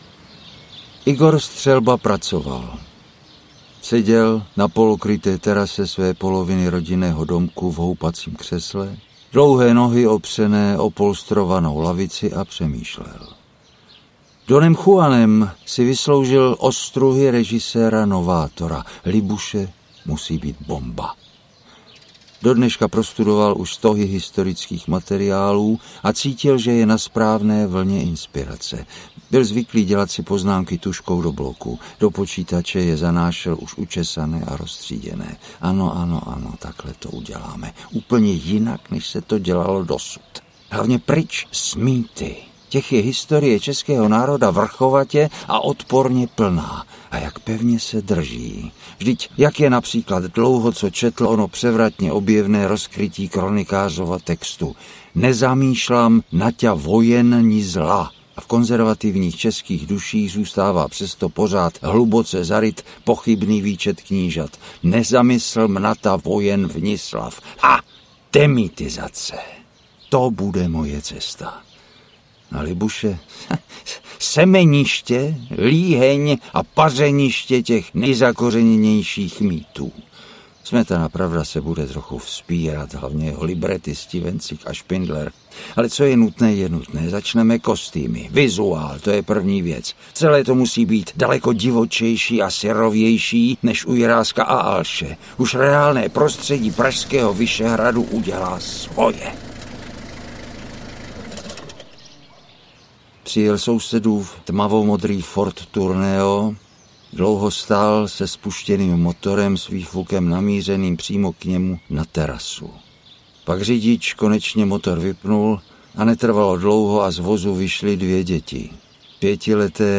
Snad jsem zas tolik neřekl audiokniha
Ukázka z knihy
V tragikomické povídce, kterou sám načetl, využívá autor své zkušenosti z působení v reklamní agentuře a showbyznysu. Příběh nechává posluchače nahlédnout do zákulisí těchto odvětví podnikání, které může být pro nezasvěceného člověka překvapivé až neuvěřitelné…
• InterpretJiří Štědroň